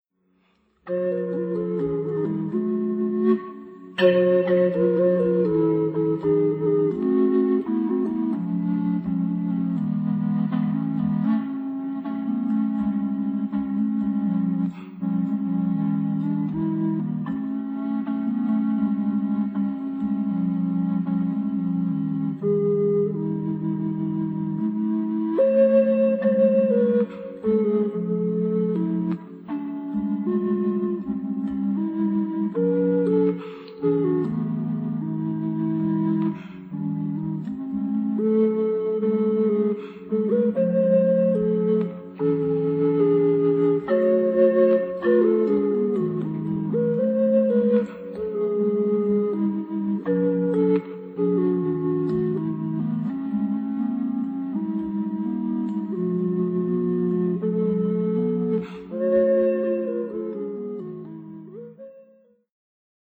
Relaxační a Meditační hudba
Použité nástroje: huaca.
Verbální vedení: Neverbální